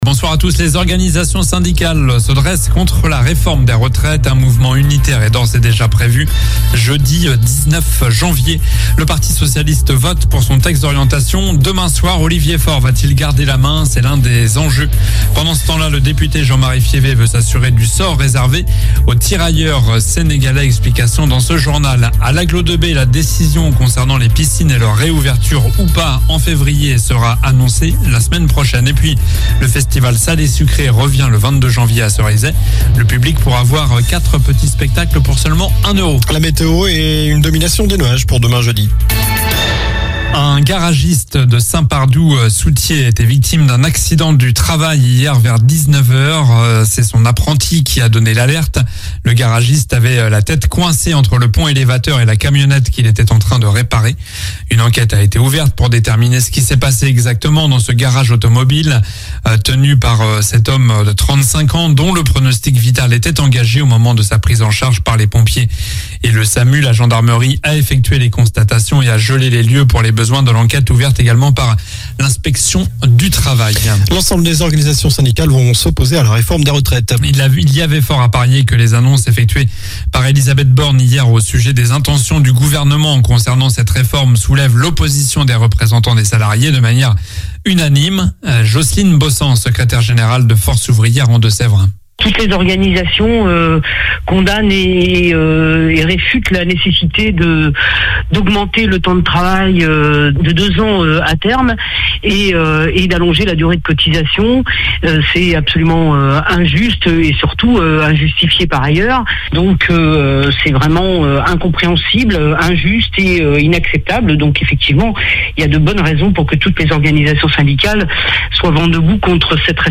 Journal du mercredi 11 janvier (soir)